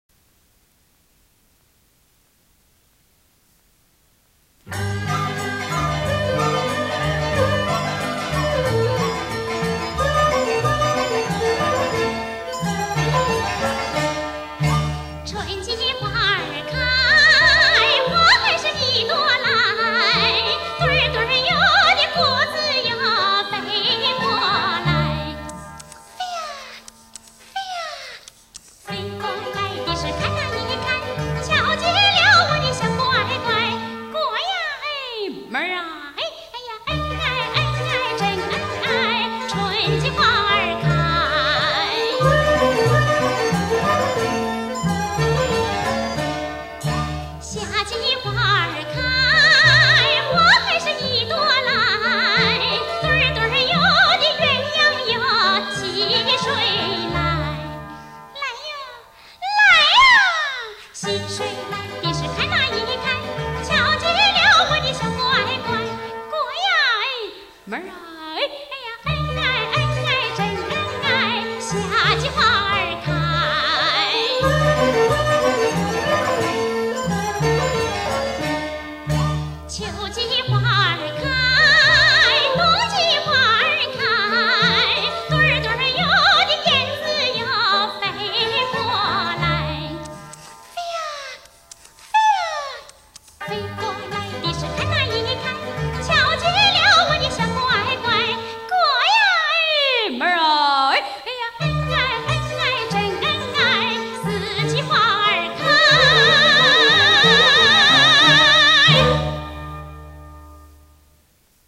虽然这只是一次排练的录音，整场音乐会依然比较完美。
湖南（张家界）民歌